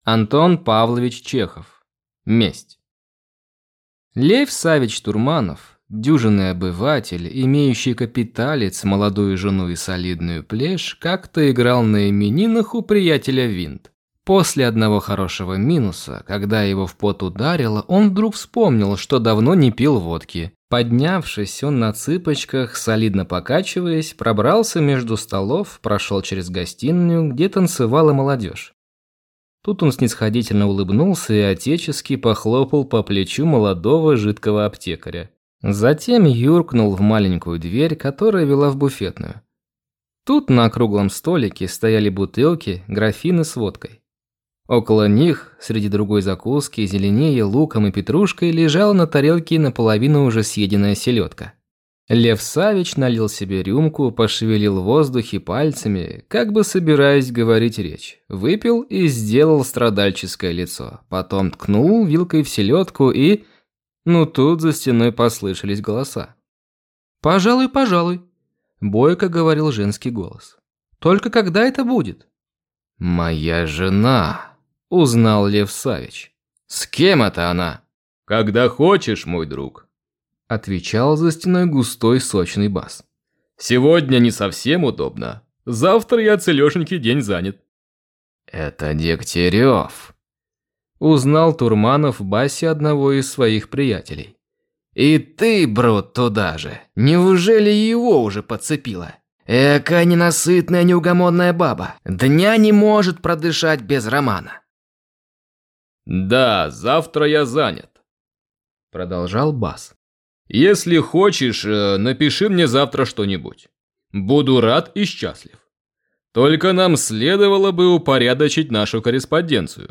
Аудиокнига Месть | Библиотека аудиокниг